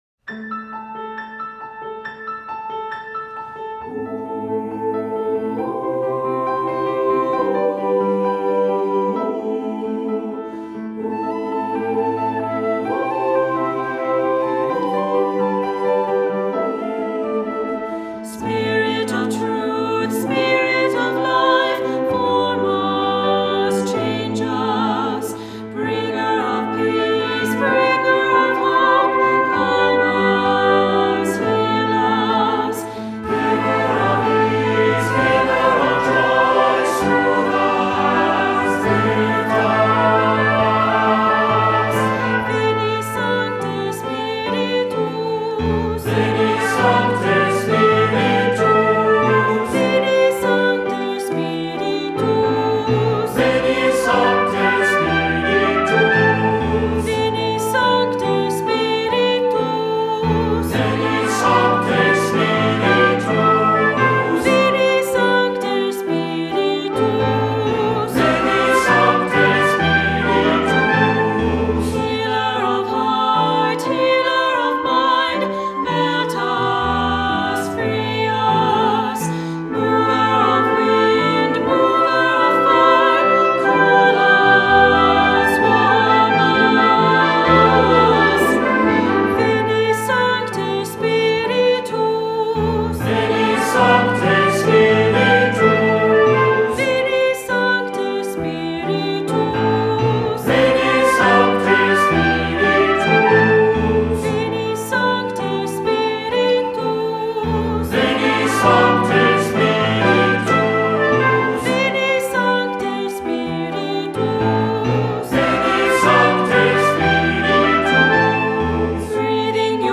Voicing: Cantor,Assembly,SATB